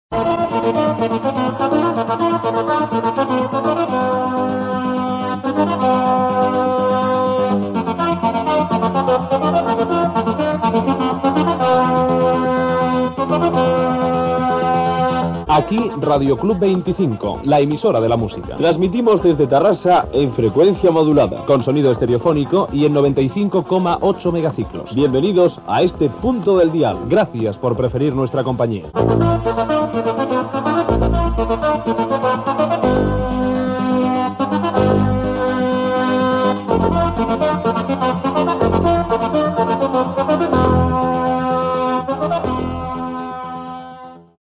Obertura d'emissions del dia amb sintonia i identificació.